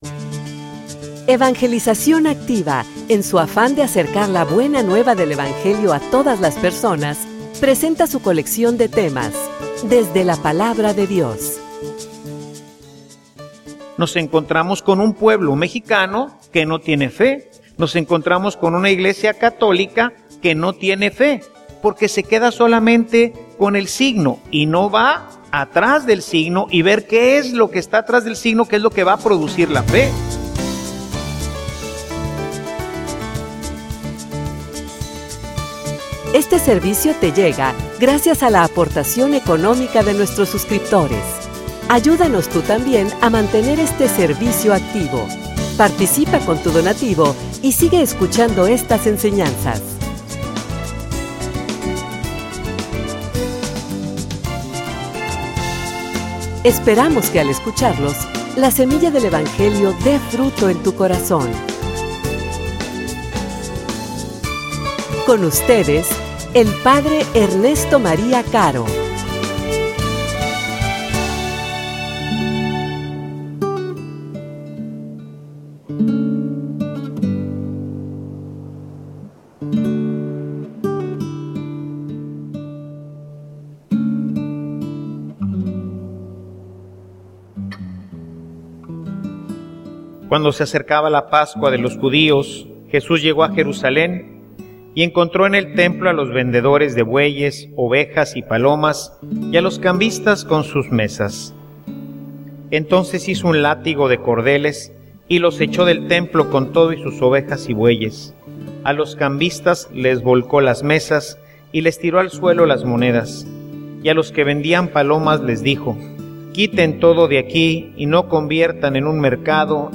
homilia_Desde_los_signos_de_la_fe.mp3